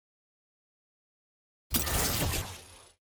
sfx-tier-wings-promotion-from-gold.ogg